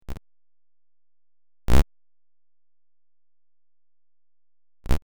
All styles of microsound are welcome.